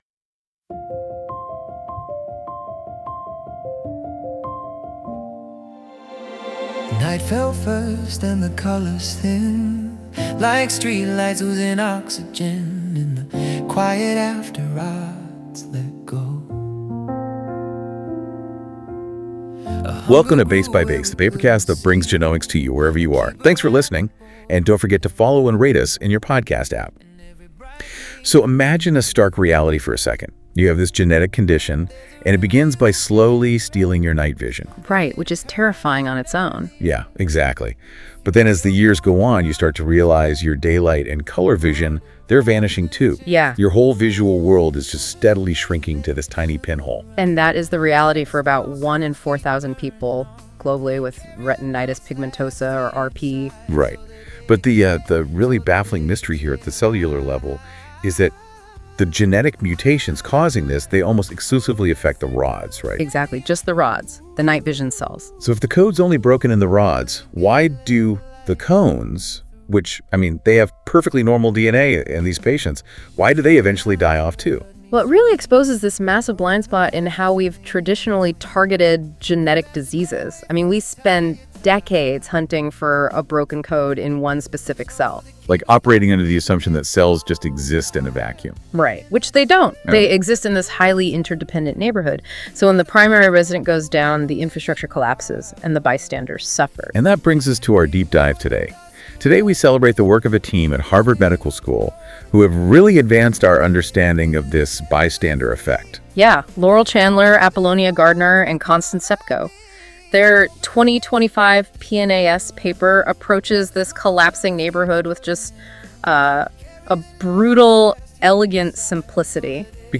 PNAS - RPE-specific MCT2 gene delivery preserves cones and vision in retinitis pigmentosa models Music:Enjoy the music based on this article at the end of the episode.